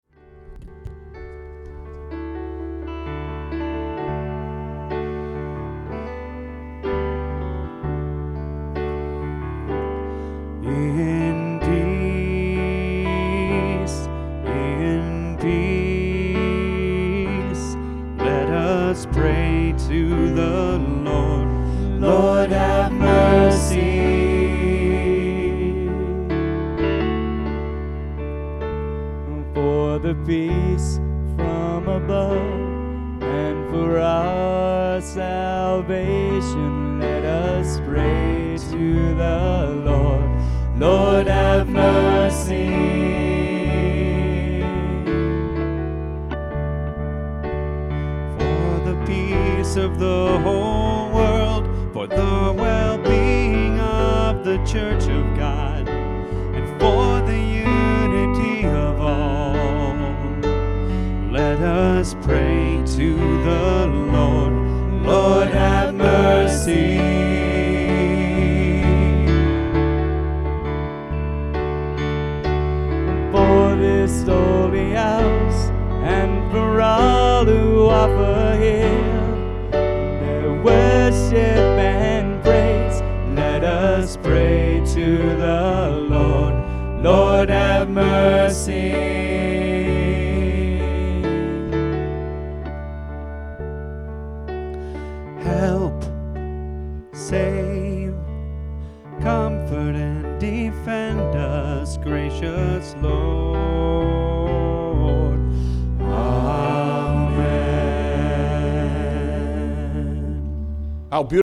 MP3 live worship version
The live recording shows that it also works with a more chordal accompaniment.
in_peace_live.mp3